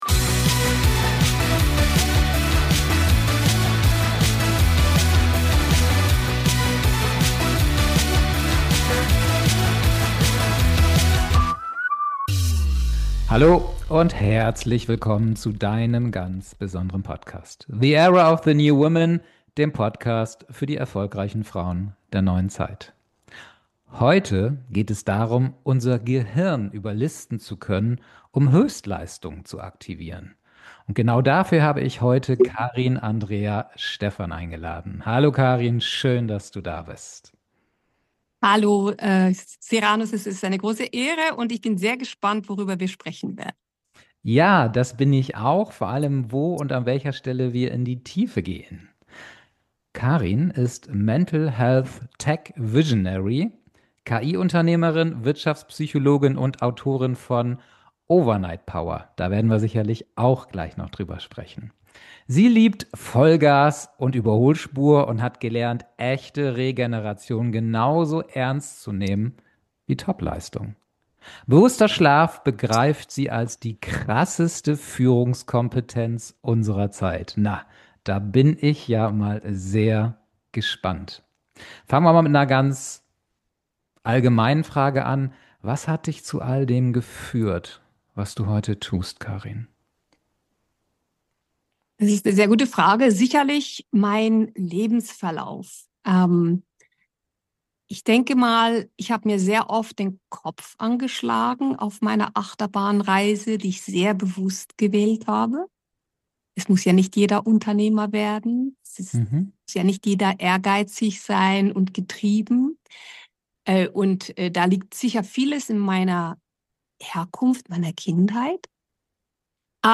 Podcast-News